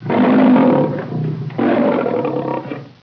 lion_pit.wav